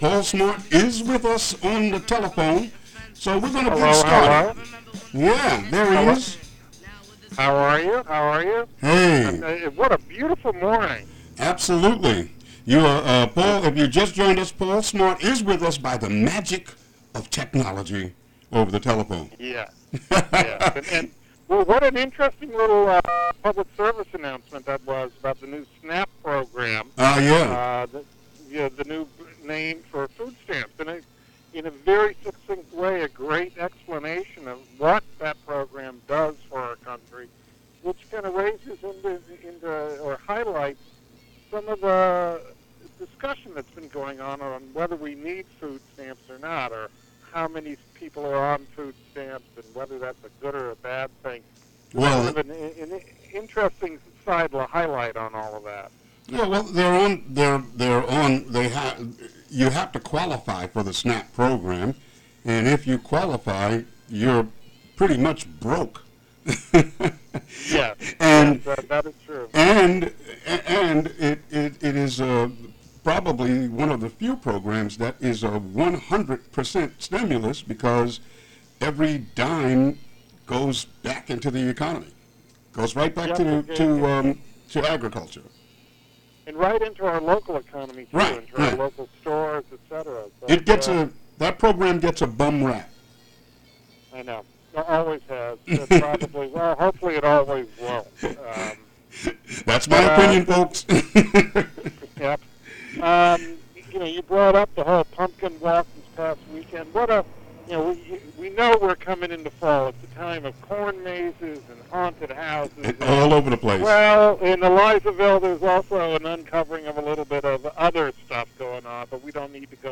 Weekly news roundup